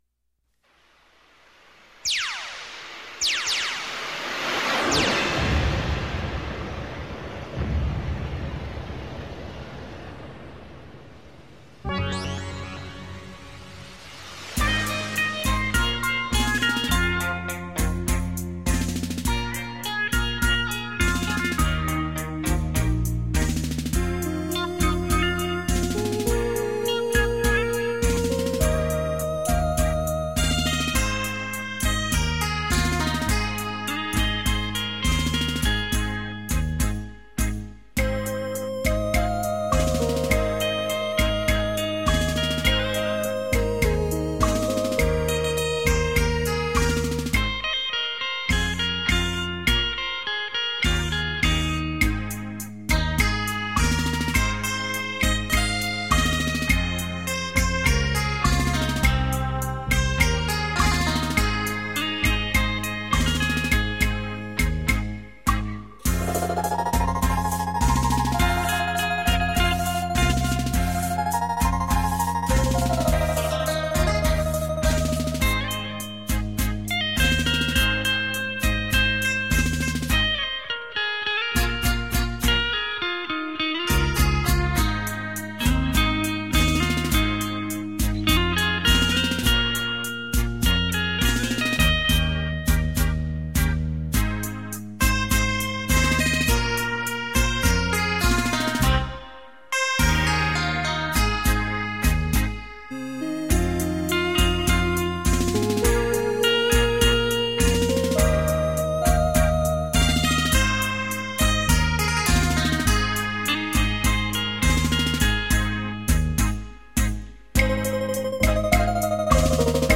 突破Hi-Fi立體境界展現音響三度空間、
繞場立體音效 發燒音樂重炫、
電腦16位元超傳真MiDi錄音、音質柔潤細緻令人陶醉、
電聲演繹發燒珍品·值得您精心收藏·細細聆賞...